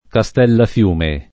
vai all'elenco alfabetico delle voci ingrandisci il carattere 100% rimpicciolisci il carattere stampa invia tramite posta elettronica codividi su Facebook Castellafiume [ ka S t H llaf L2 me o ka S t H llaff L2 me ] top.